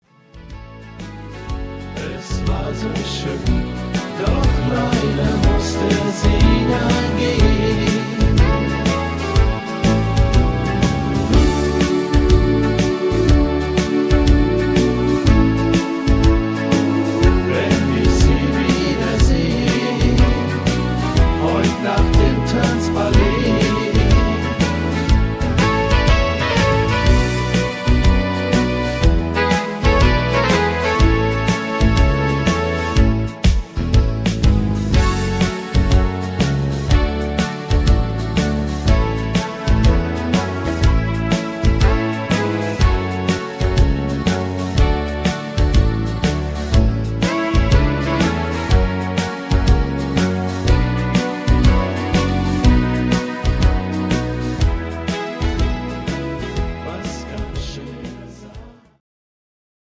Rhythmus  Foxtrott
Art  Deutsch, Schlager 2000er